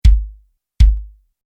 Drumset-Mikrofonierung
Position 2: ...vor der Bassdrum in ca. 5cm bis max. 15 cm Abstand
Bei solch einer Mikrofonierung dominiert eher der Ton der Bassdrum als der Attack den Gesamtklang, daher eignet sie sich sehr gut für Jazz und leisere Musikstile, bei denen vorwiegend akustische Instrumente eingesetzt werden.